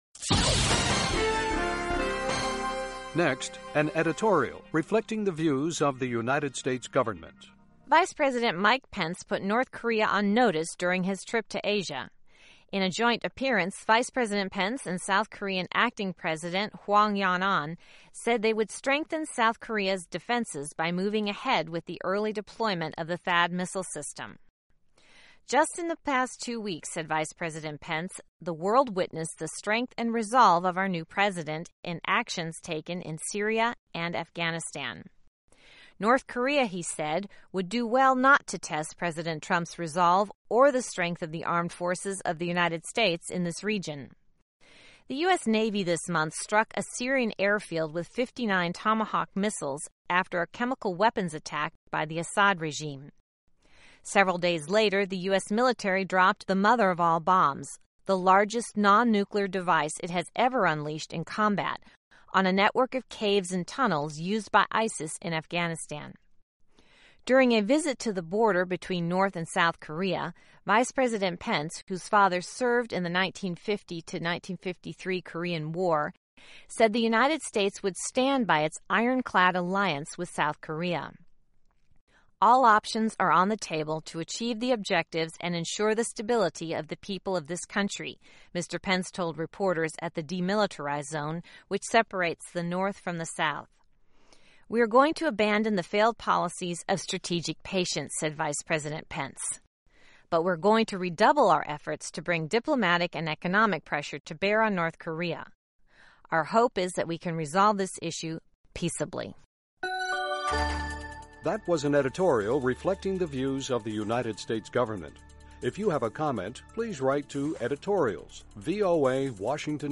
Reflecting the Views of the U.S. Government as Broadcast on The Voice of America